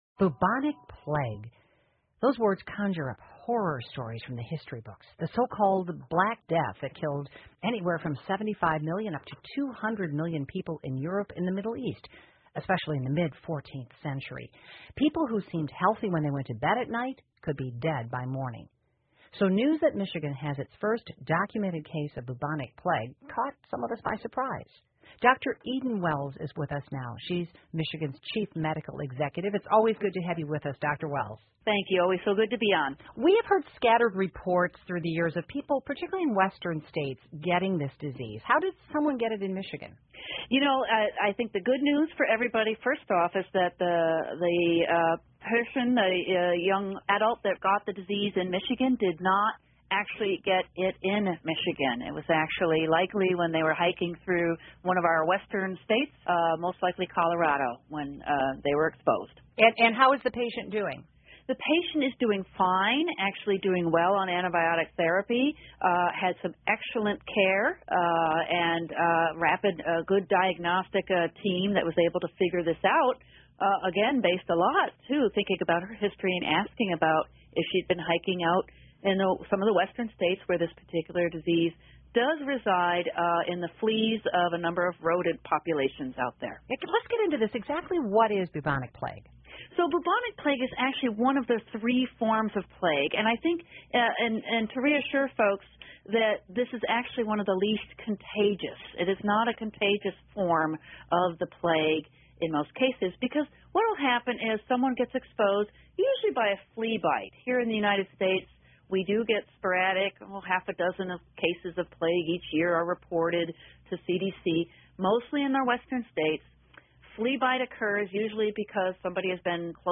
密歇根新闻广播 密歇根出现黑死病,可是这次没什么可怕得了!